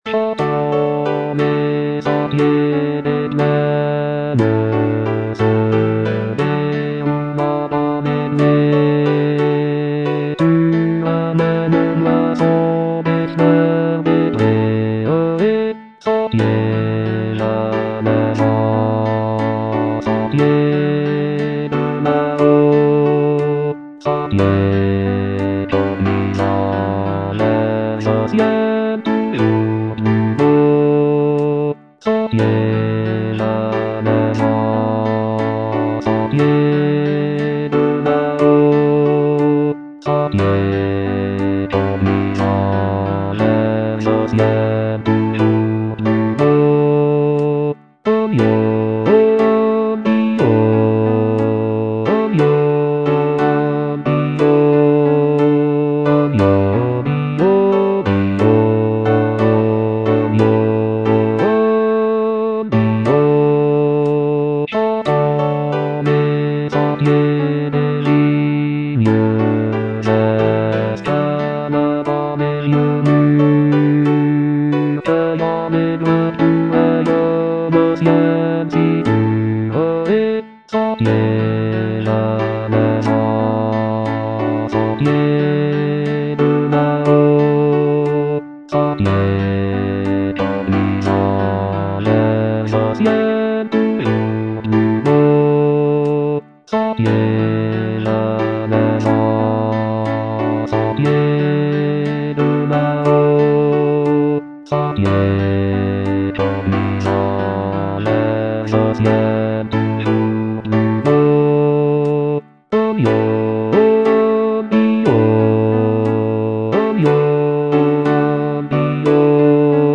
Bass II (Voice with metronome)